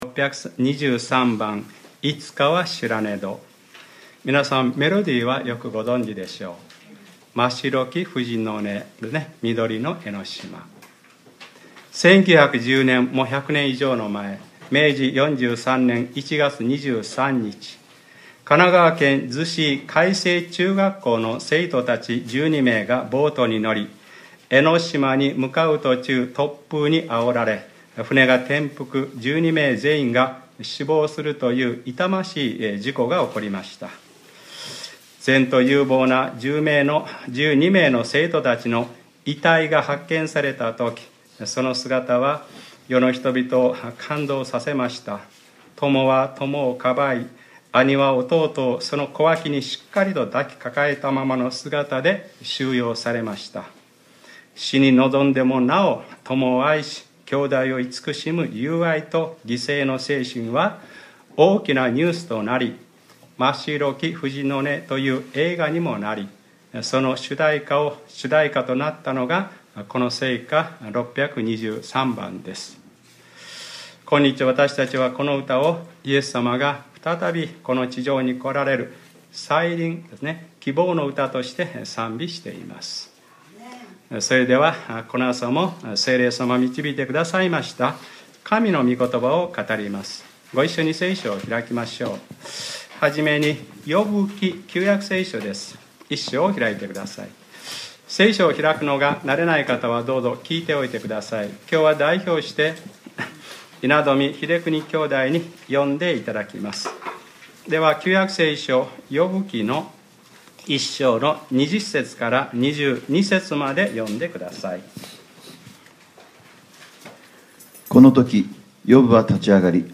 2014年9月21日（日）礼拝説教 『愛する者の死に向き合う』